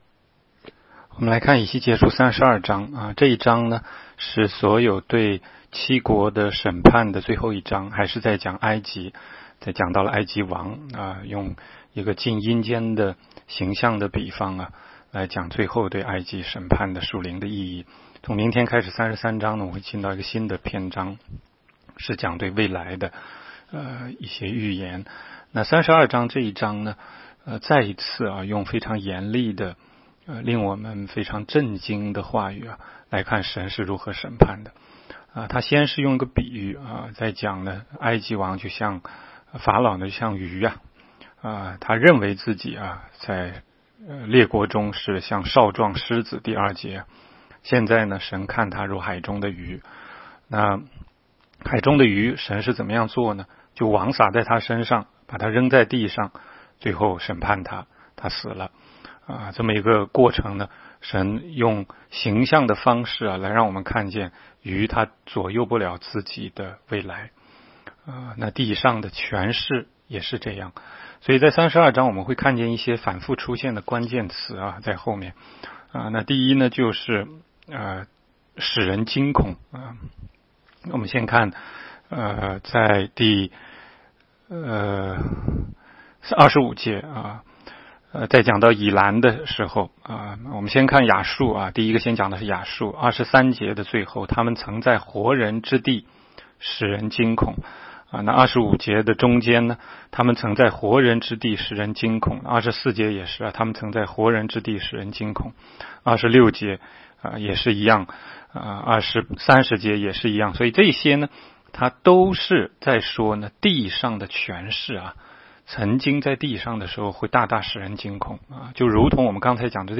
16街讲道录音 - 每日读经 -《以西结书》32章